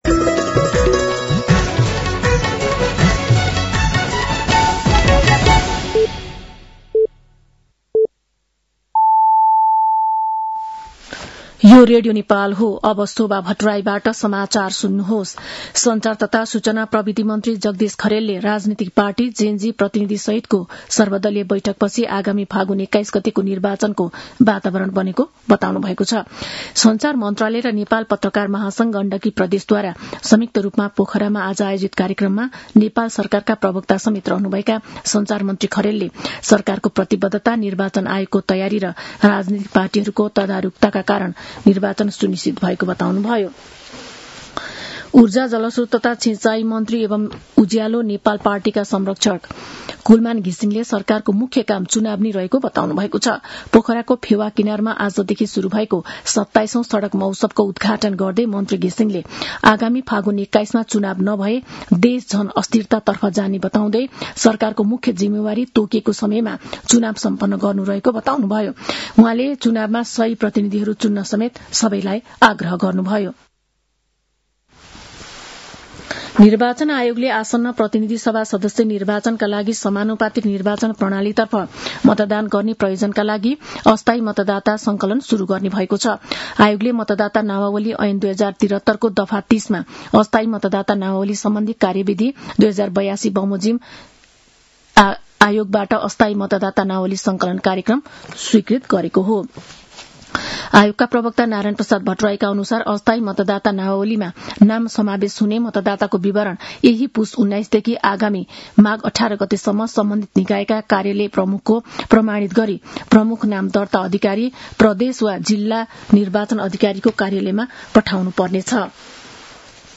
साँझ ५ बजेको नेपाली समाचार : १३ पुष , २०८२
5.-pm-nepali-news-1-5.mp3